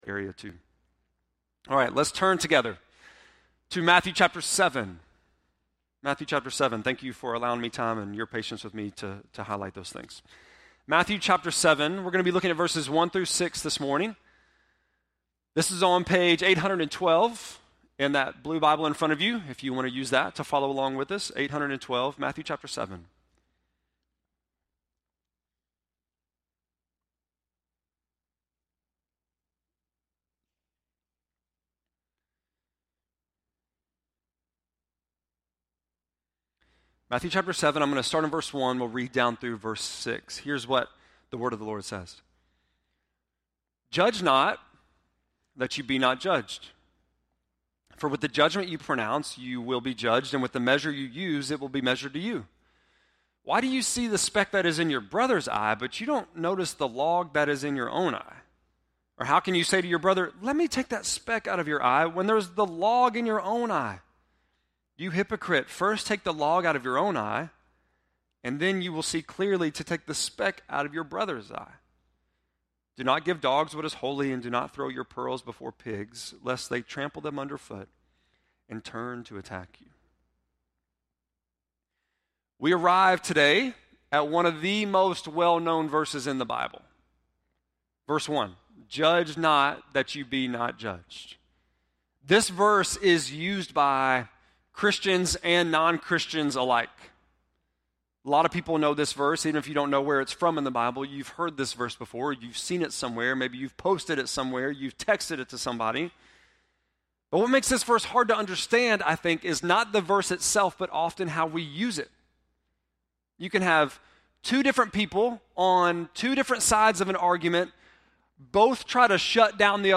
5.12-sermon.mp3